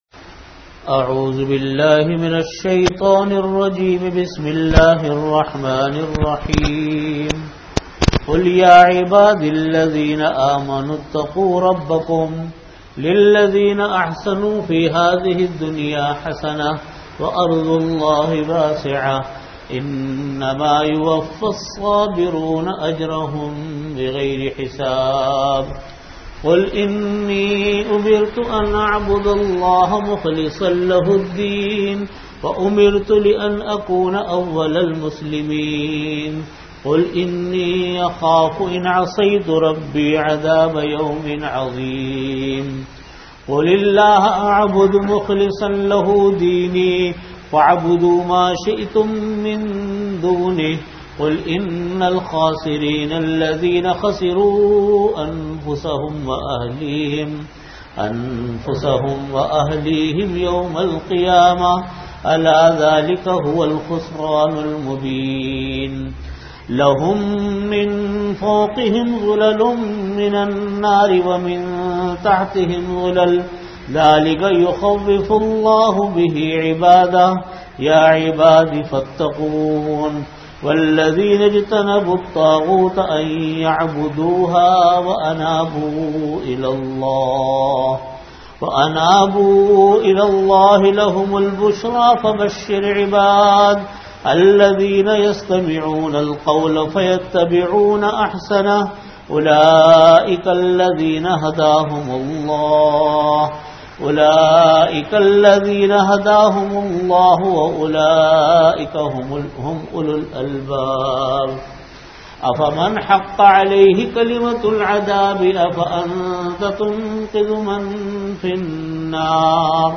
Audio Category: Tafseer
44min Time: After Asar Prayer Venue: Jamia Masjid Bait-ul-Mukkaram, Karachi